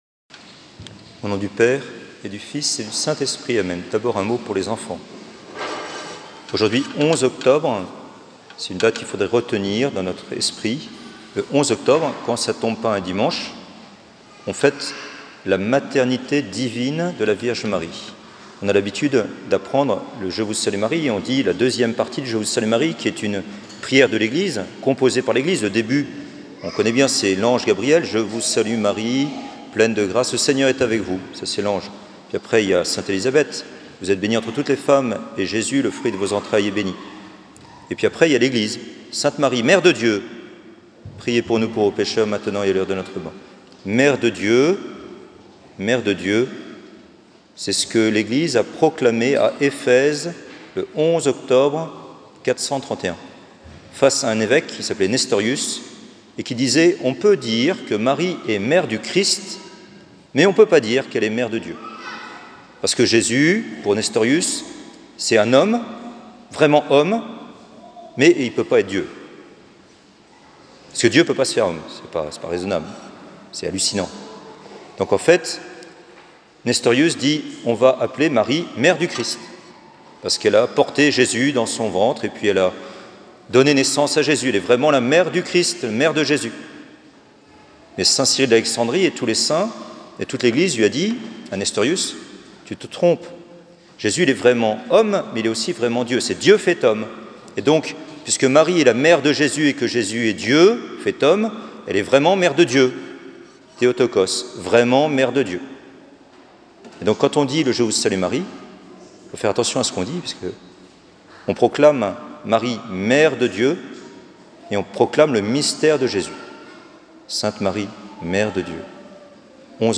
Homélies du dimanche